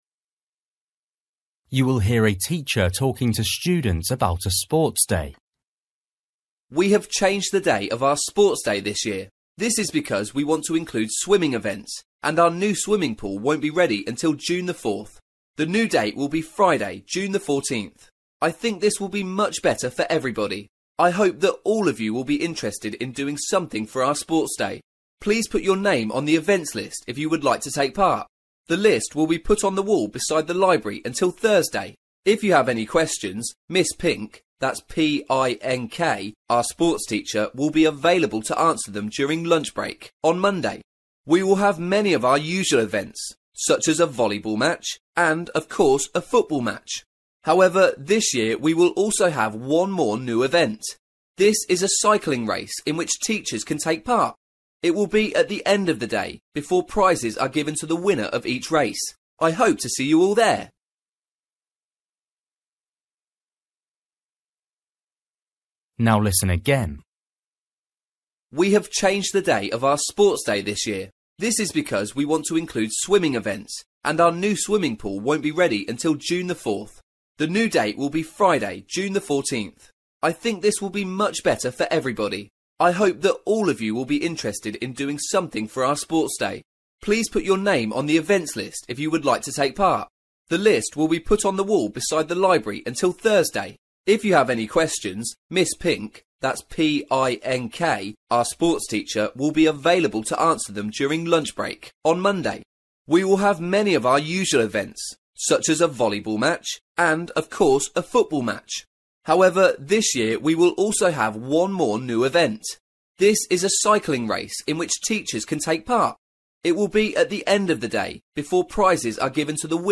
You will hear a teacher talking to students about a sports day.